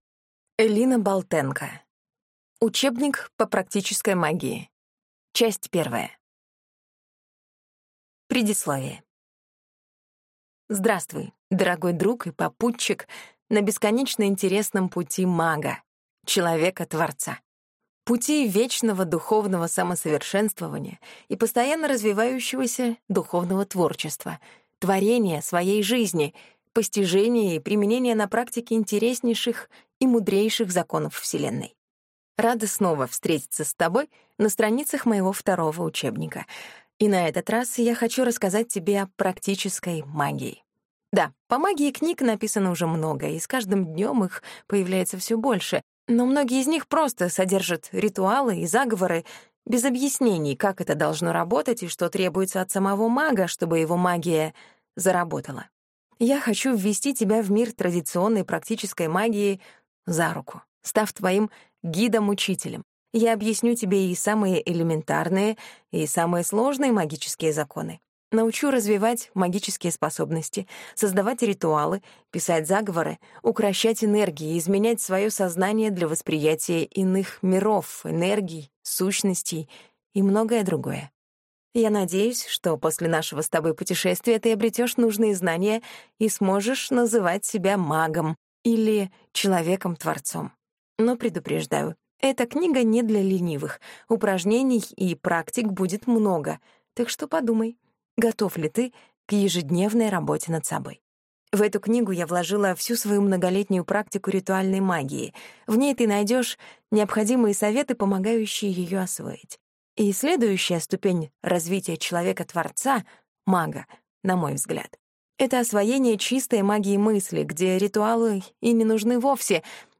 Аудиокнига Учебник по практической магии. Часть 1 | Библиотека аудиокниг